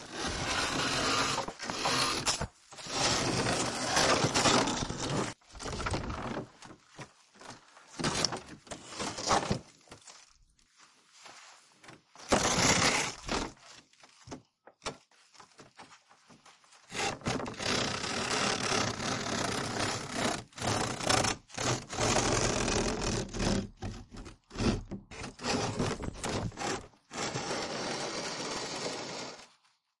木材破坏 " 木板围栏拉刮吱吱作响
我在后花园里打破了一块腐烂的旧篱笆，以为我会和世界分享所产生的声音！
Tag: 面板 吱吱 木材 刮去 围栏